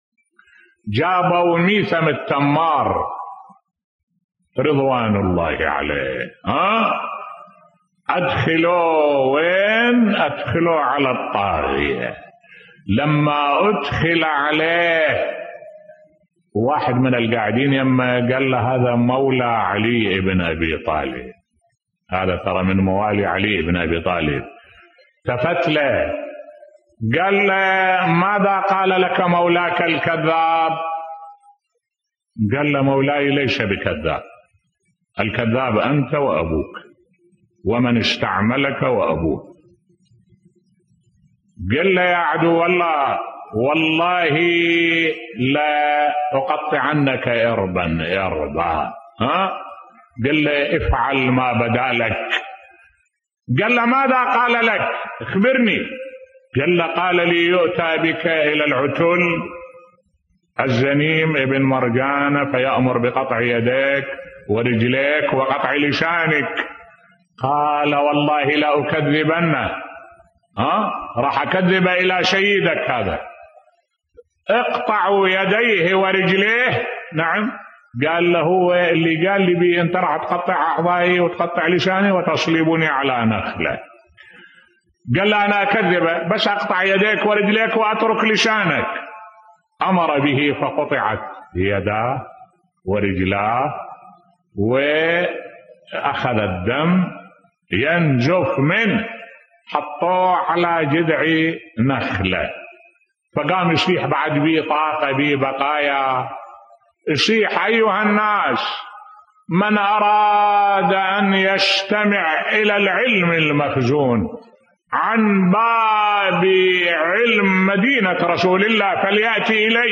ملف صوتی فدائية ميثم التمار رض و صلابته في الدين و حب أمير المؤمنين (ع) بصوت الشيخ الدكتور أحمد الوائلي